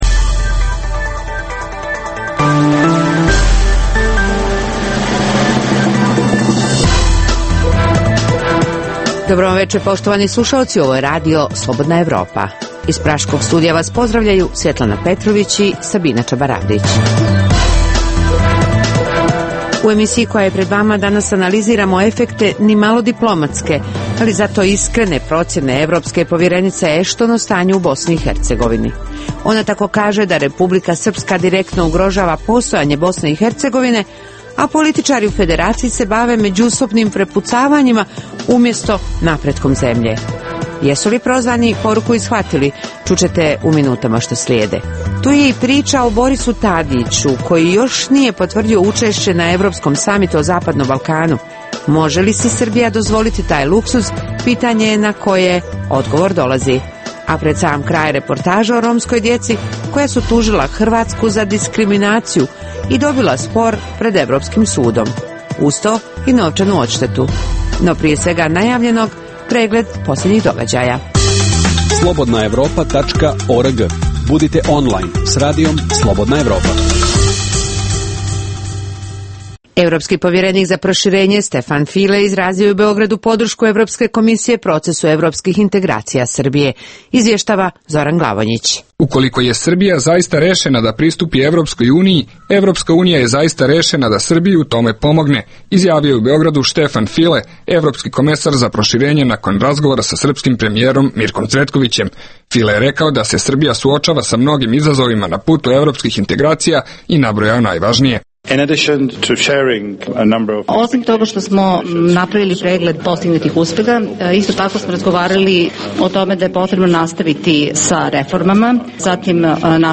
Emitujemo i reportažu o romskoj djeci koja su tužila Hrvatsku za diskriminaciju i dobila spor pred Evropskim sudom